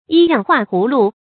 注音：ㄧ ㄧㄤˋ ㄏㄨㄚˋ ㄏㄨˊ ㄌㄨˊ
依樣畫葫蘆的讀法